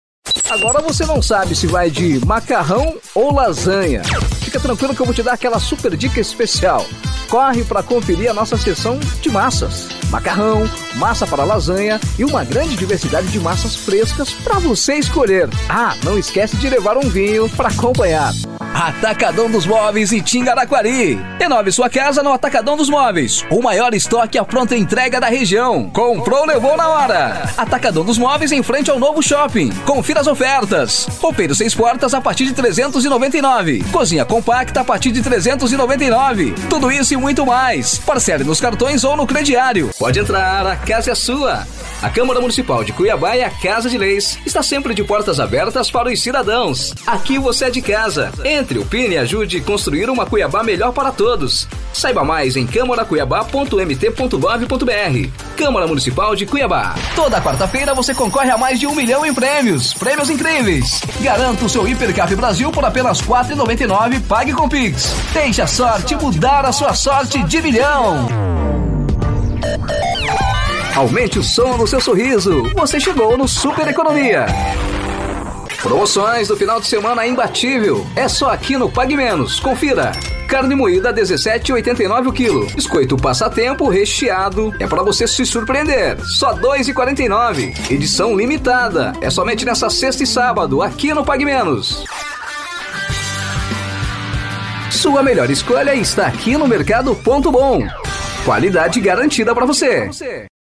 Spot Comercial
Vinhetas
Animada